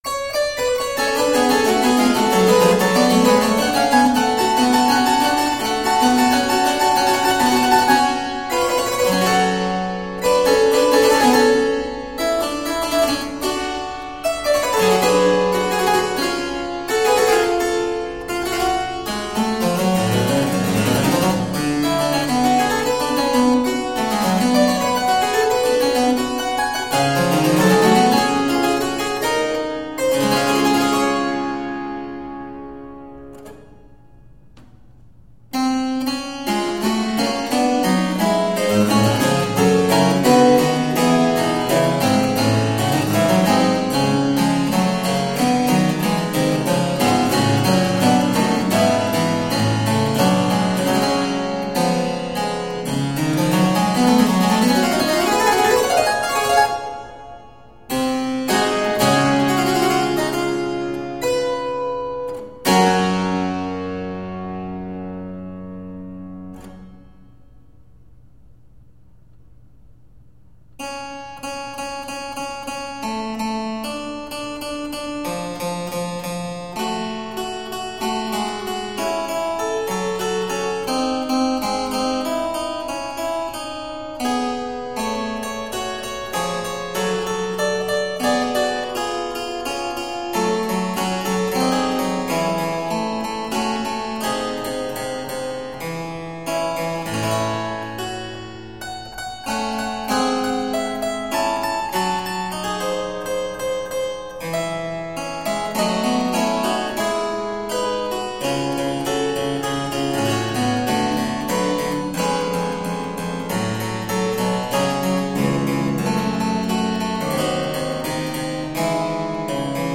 Harpsichord and fortepiano classics.